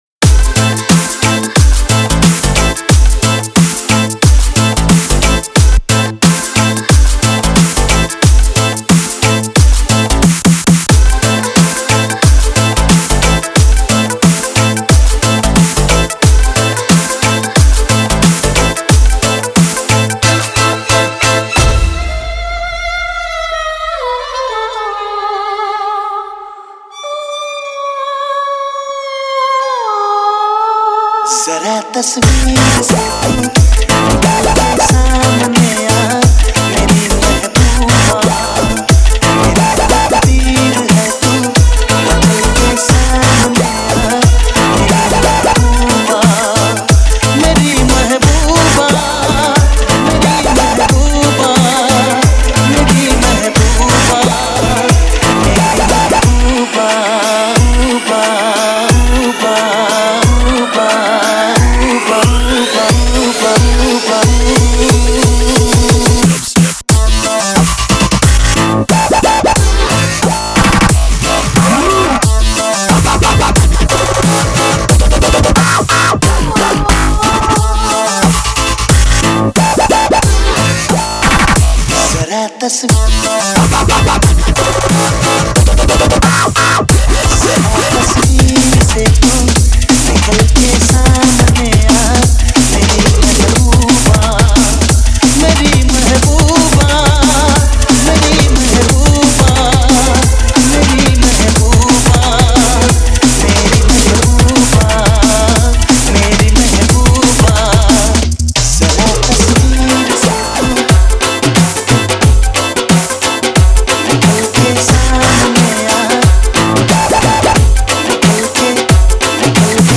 DJ Remix Songs